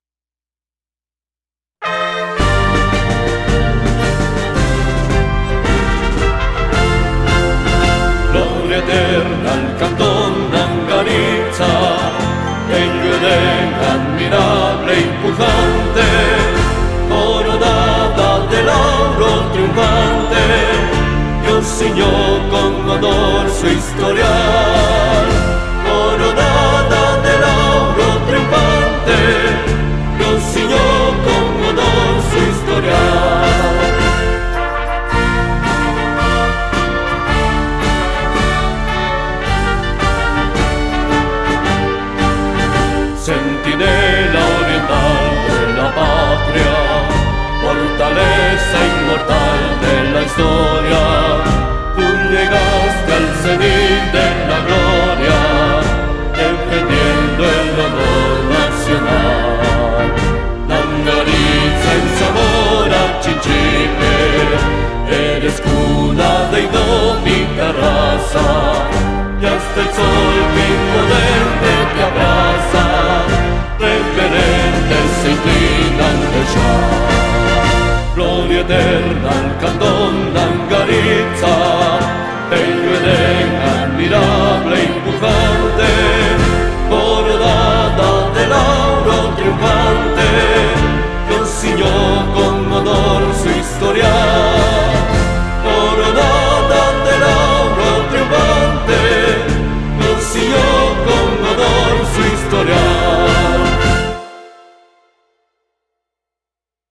Himno del Cantón Nangaritza